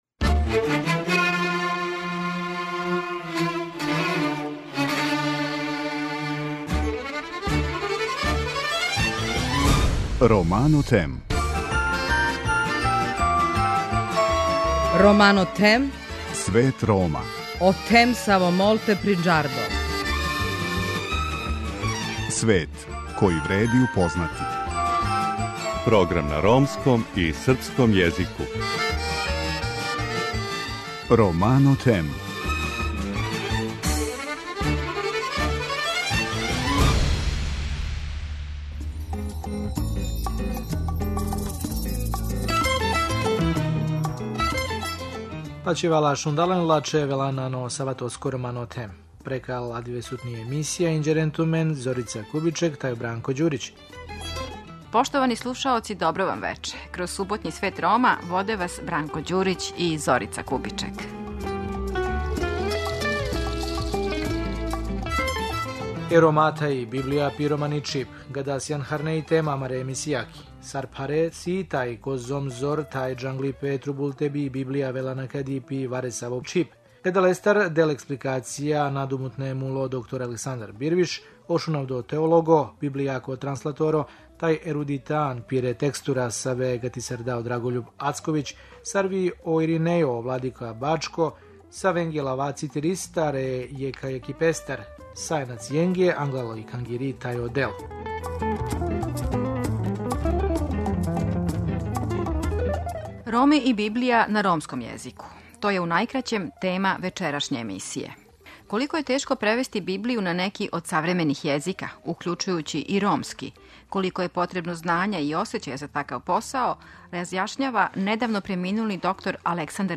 У вечерашњој емисији чућемо и речи владике бачког Иринеја Буловића.